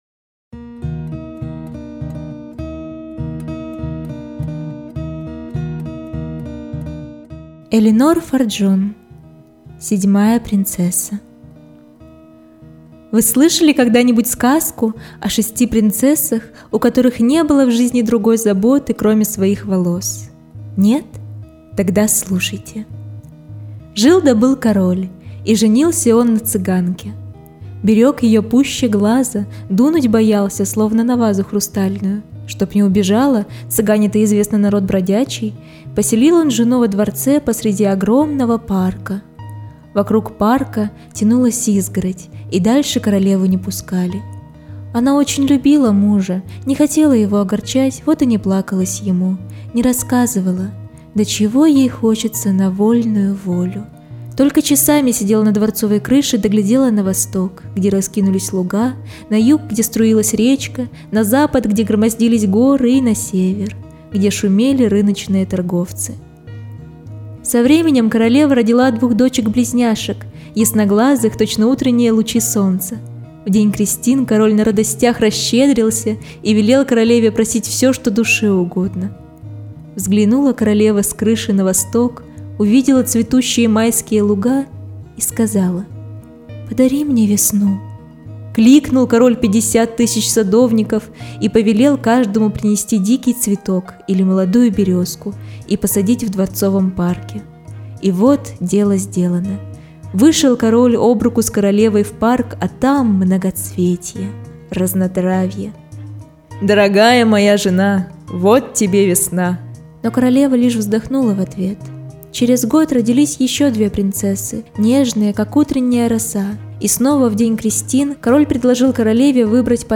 Седьмая принцесса - аудиосказка Элинор Фарджон - слушать онлайн